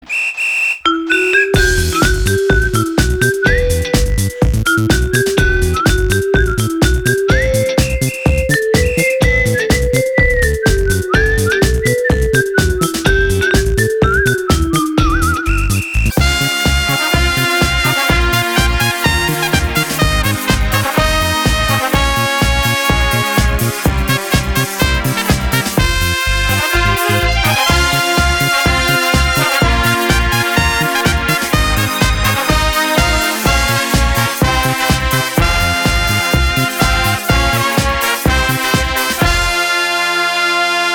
• Качество: 320, Stereo
инструментальные
OST